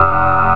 SOUND\BUZZ.WAV
1 channel